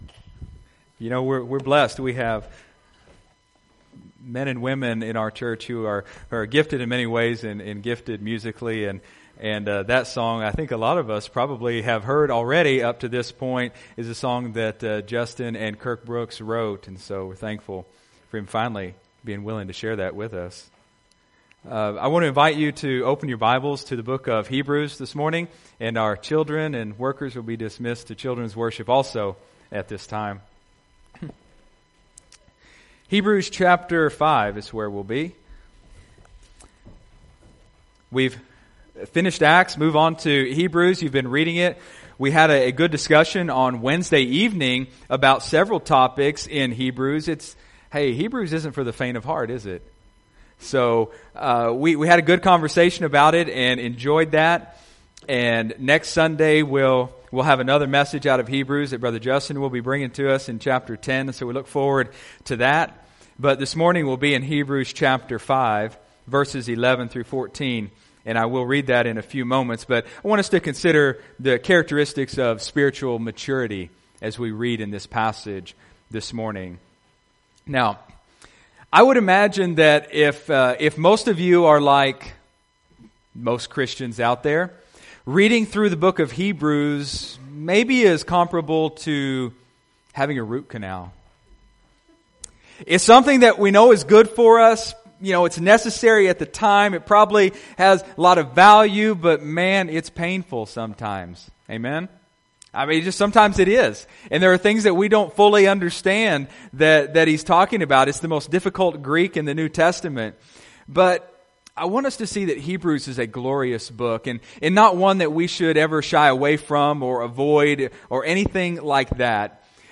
Sunday, March 12, 2017 (Sunday Morning Service)